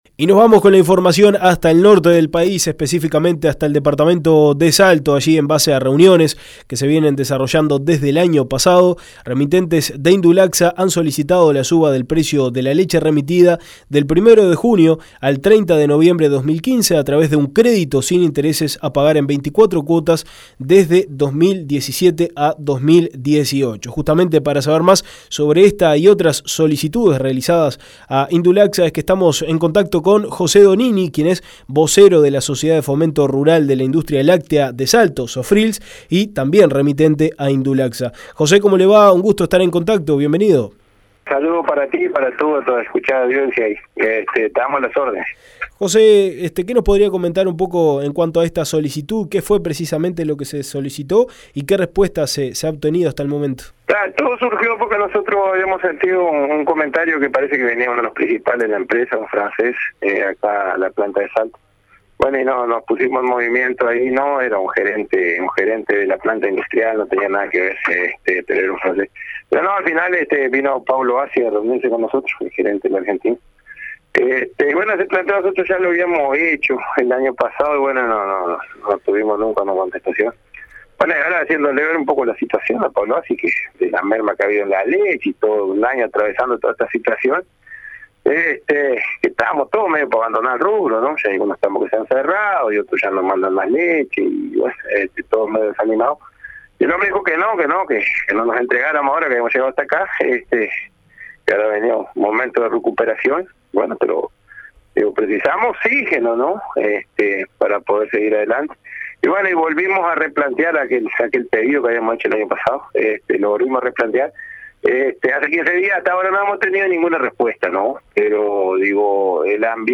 El integrante de SOFRILS, en diálogo